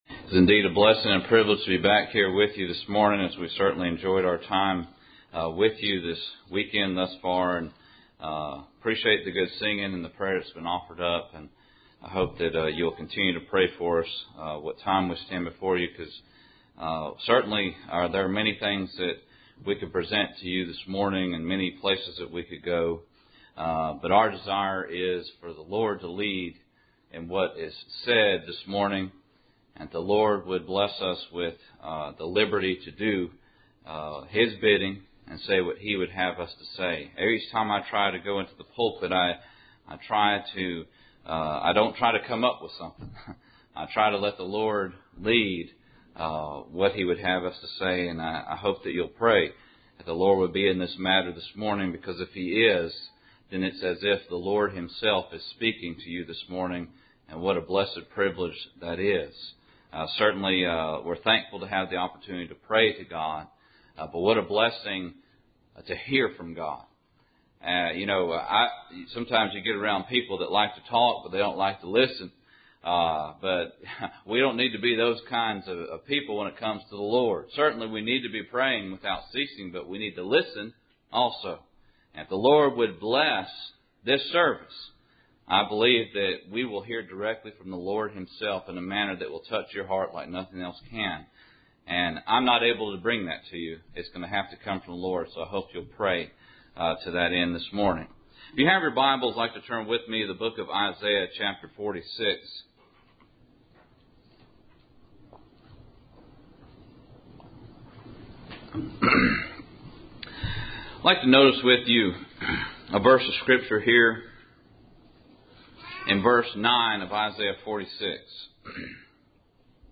Service Type: Cool Springs PBC August Annual Meeting